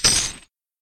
非循环音(SE)